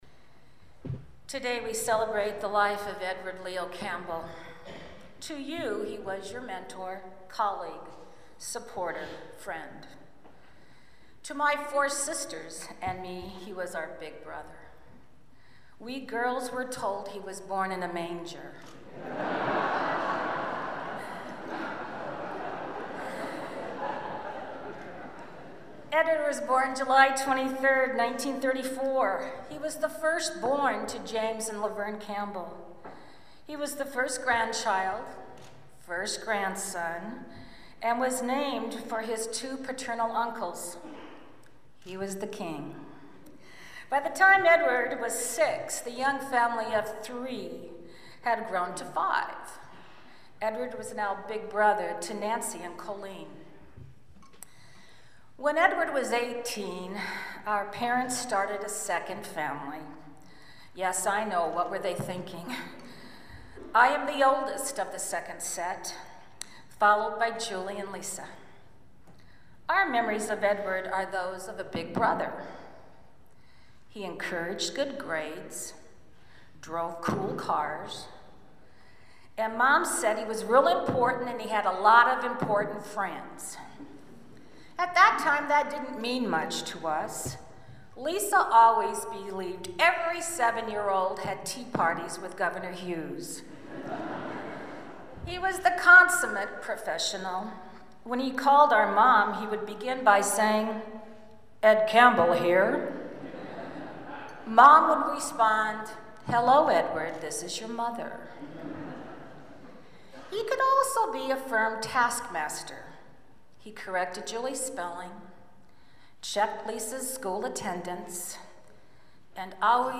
A former president, a U.S. senator, a congressman and other Iowa politicians gathered in a Des Moines church today for the funeral of former Iowa Democratic Party chairman Ed Campbell.